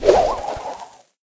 dynamike_throw_01.ogg